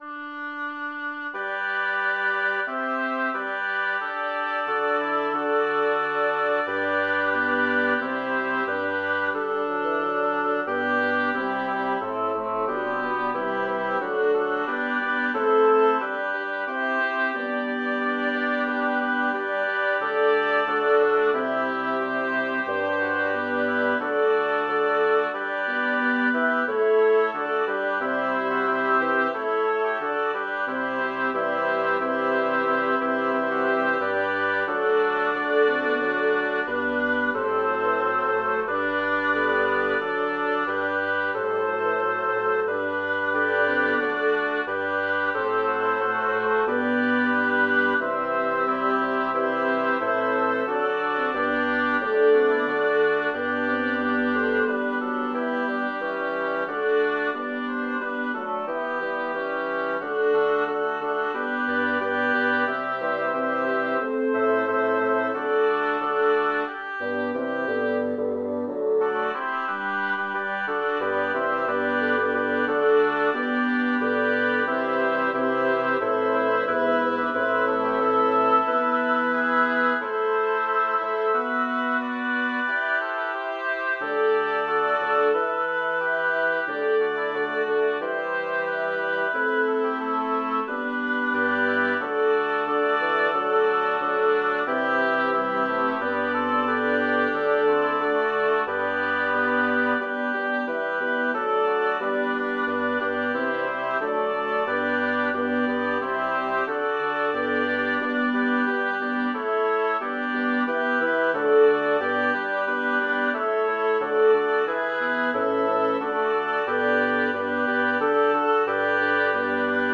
Title: Felici d'Adria e dilettose rive Composer: Andrea Gabrieli Lyricist: Number of voices: 8vv Voicing: SSAATTBB Genre: Secular, Madrigal
Language: Italian Instruments: A cappella